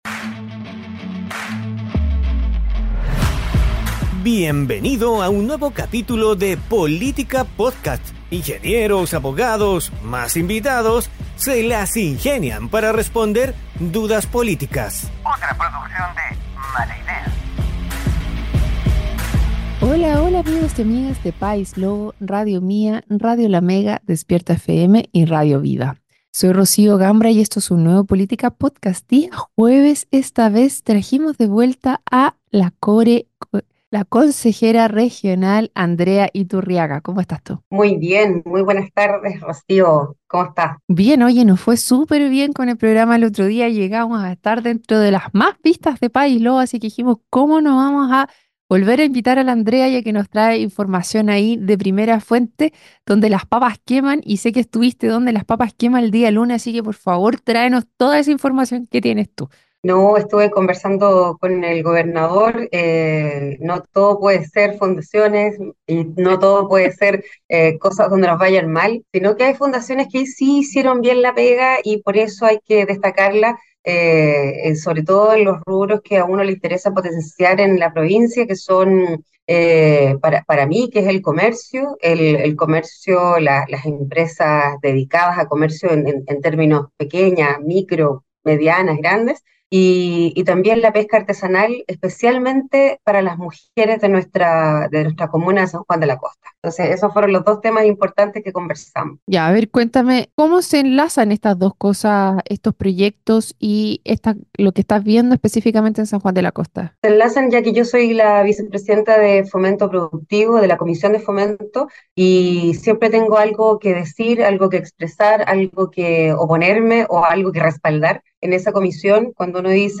conversó con la Consejera Reginal, Andrea Iturriaga, sobre el desarrollo regional y la importancia del comercio, las empresas y la pesca artesanal para las mujeres de la comuna de San Juan de la Costa.